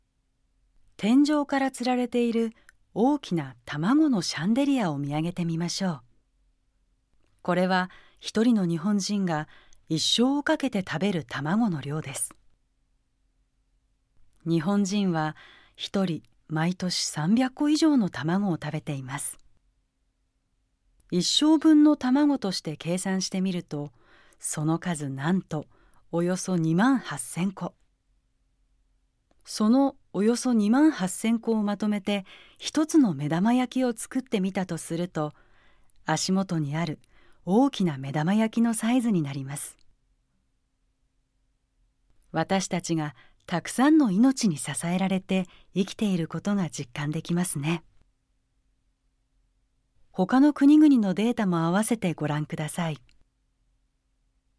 音声ガイドナレーター：宇賀なつみ